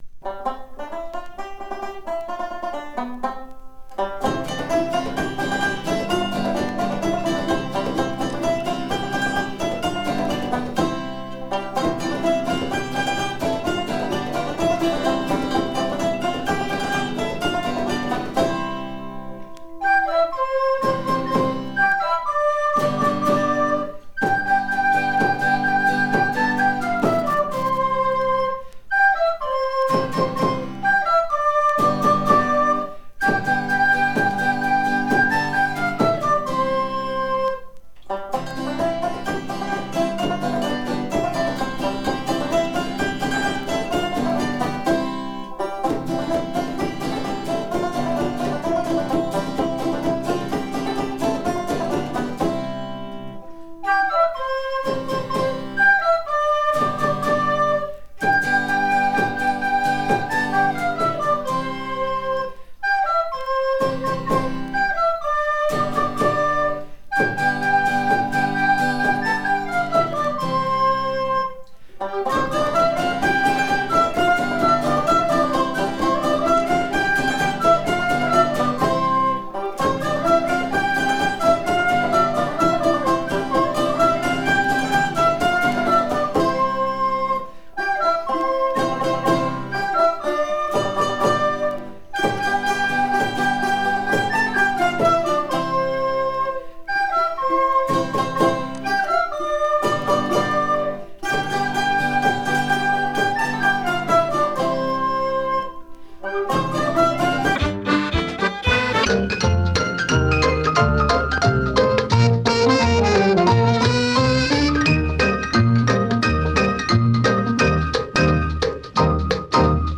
TANZMUSIKEN
Bretonische-Polka-Elbraben-11-AudioTrack-11.mp3